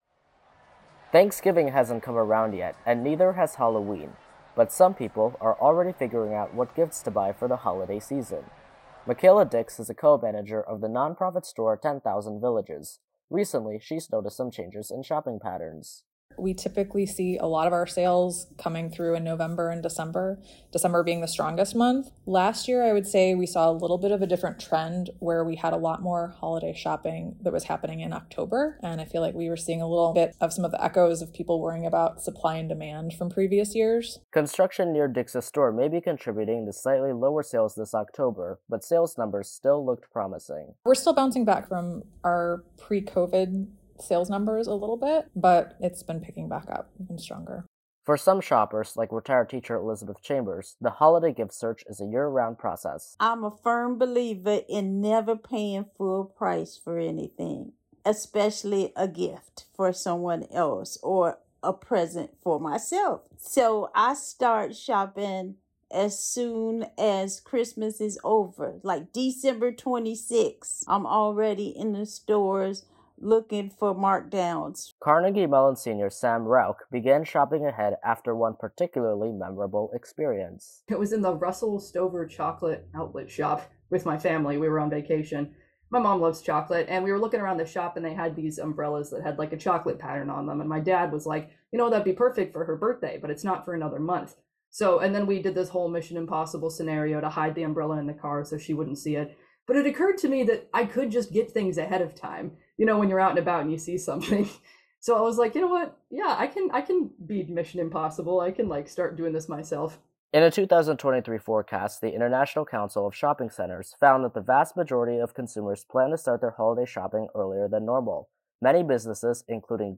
Department store sounds by Pixabay